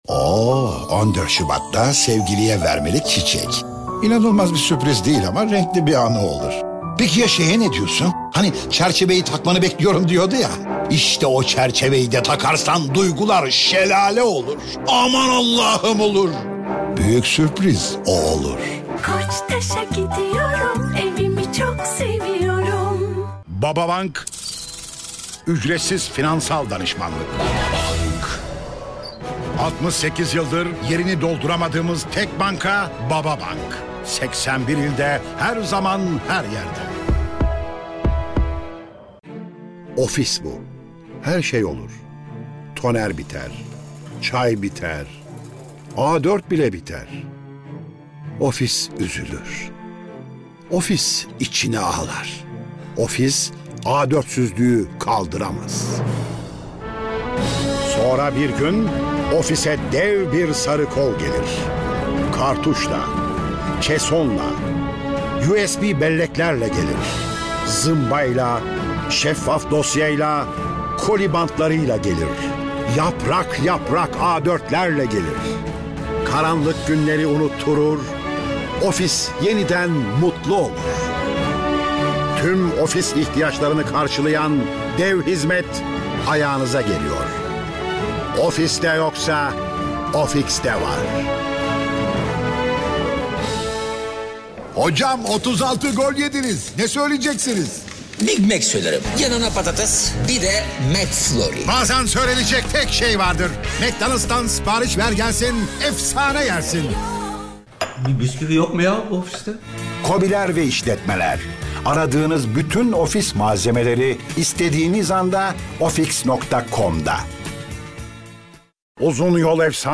Çeşitli reklamlarda seslendirme yapmıştır.